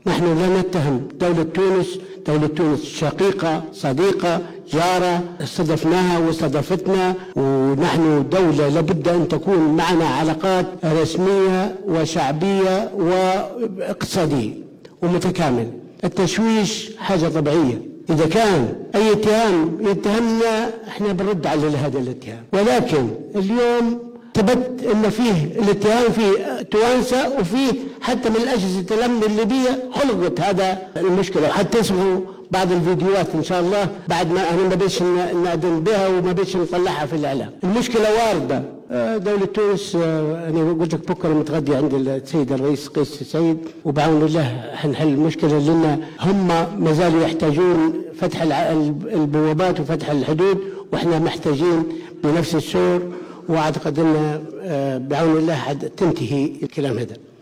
وجاء ذلك خلال جلسة يعقدها البرلمان الليبي لمساءلة الحكومة.